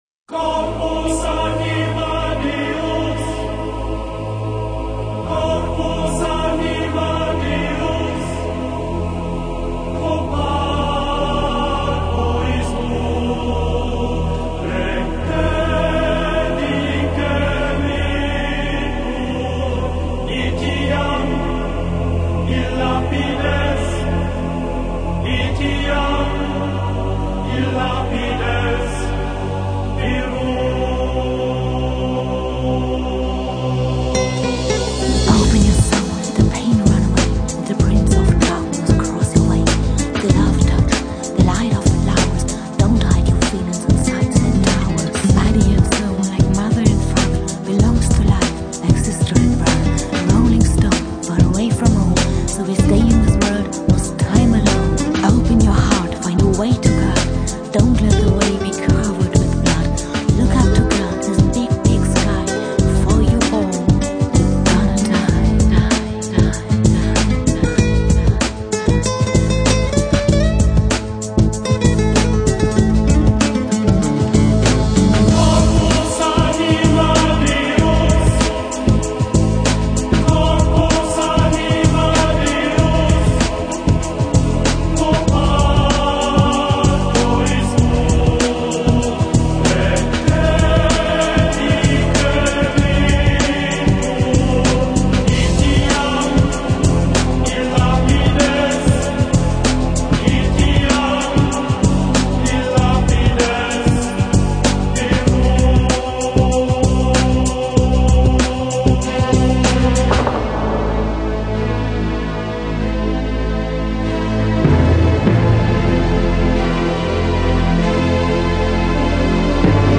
Мистическая музыка